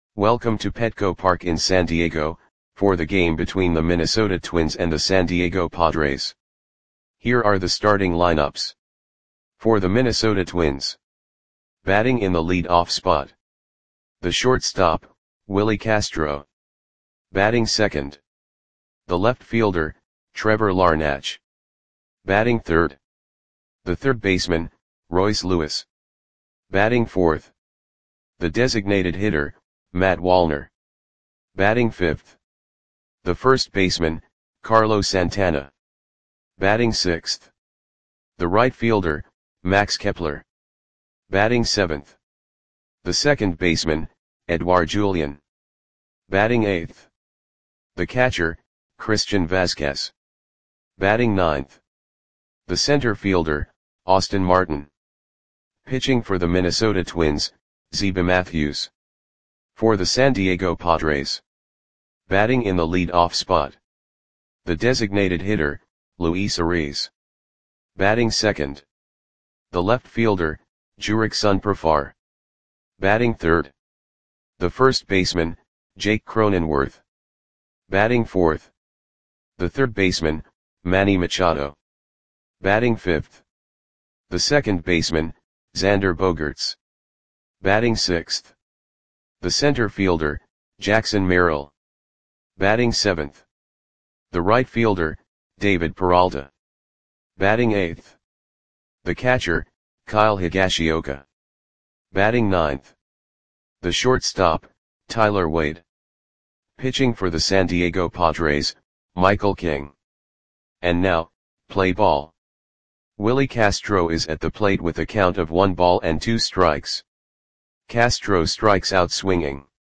Audio Play-by-Play for San Diego Padres on August 19, 2024